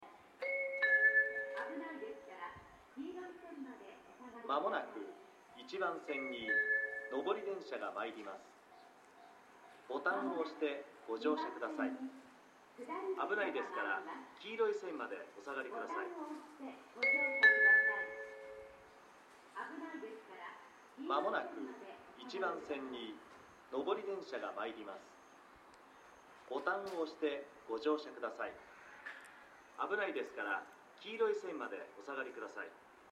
箱根ヶ崎駅１番線接近放送　　普通　八王子行き接近放送です。簡易型です。